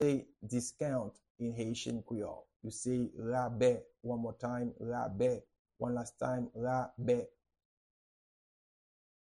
Pronunciation and Transcript:
How-to-say-Discount-in-Haitian-Creole-Rabe-pronunciation.mp3